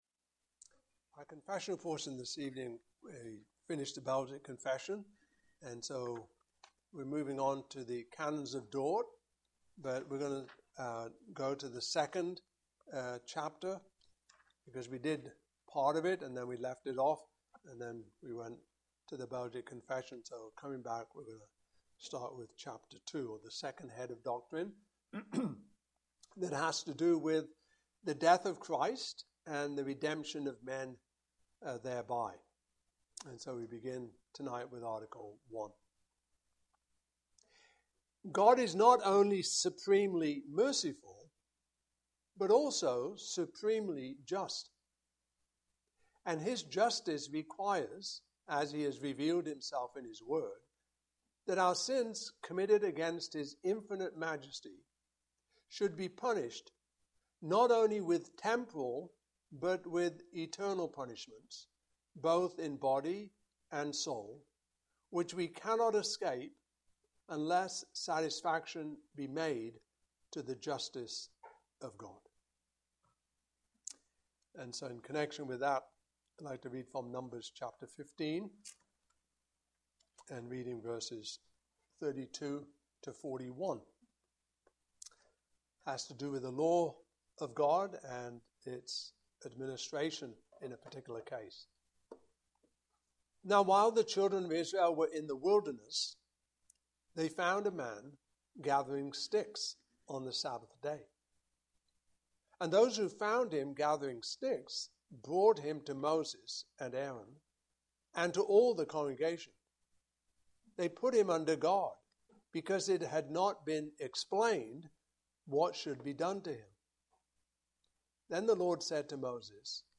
Canons of Dordt 2025 Passage: Numbers 15:32-41 Service Type: Evening Service Topics